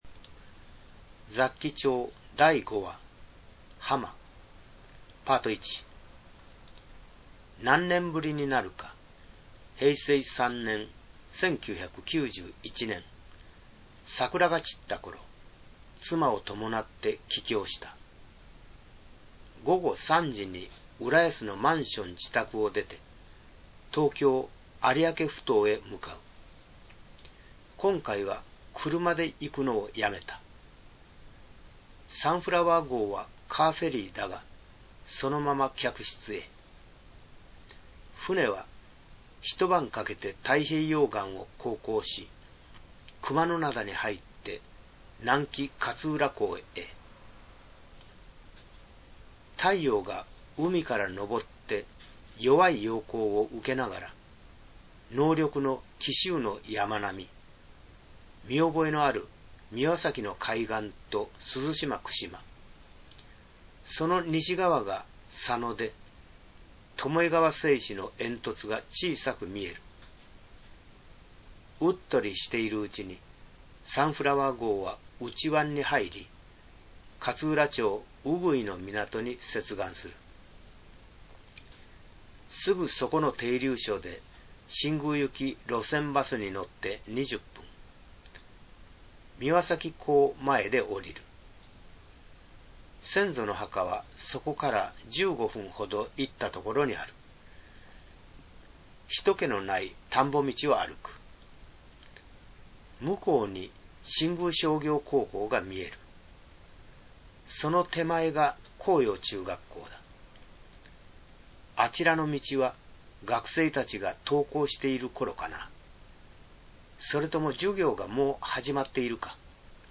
Ｐａｒｔ１　三輪崎の砂浜（はま） Ｐａｒｔ１朗読(8'19")